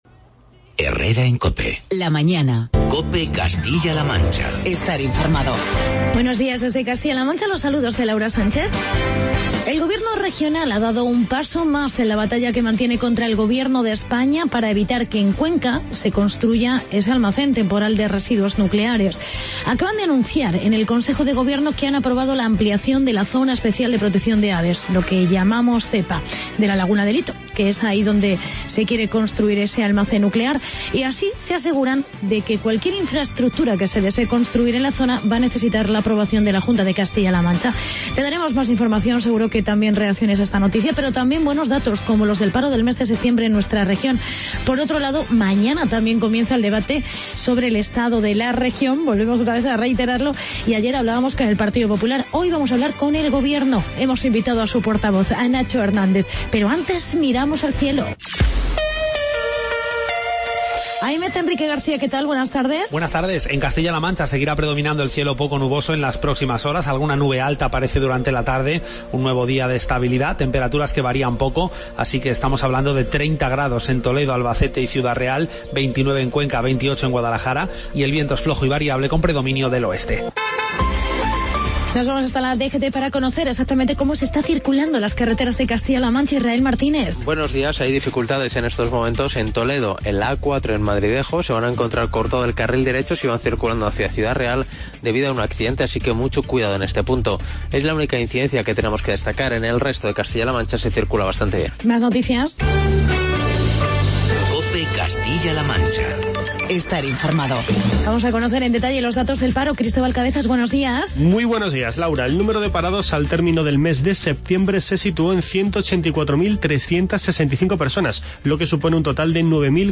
Actualidad y entrevista con el portavoz del gobierno de CLM, Nacho Hernando.